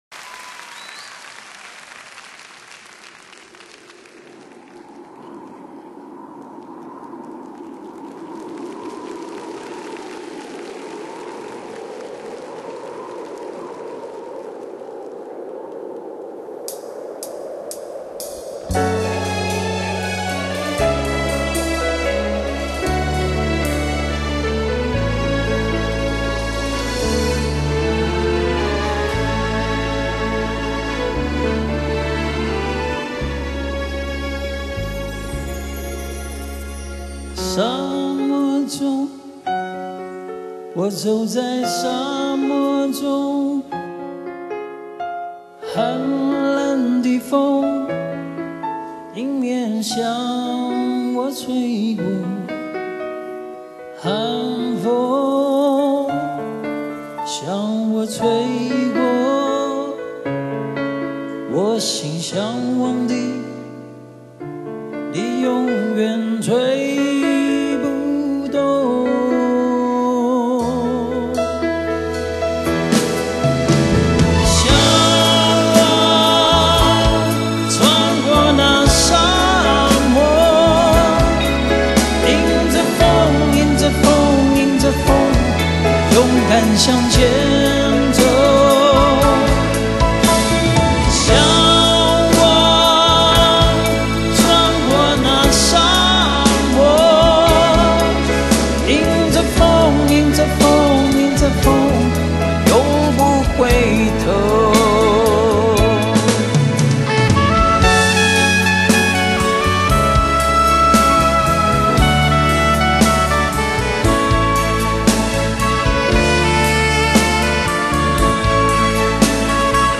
十足的現場感與衆不同，輕鬆親切活潑，將氛圍推向高潮。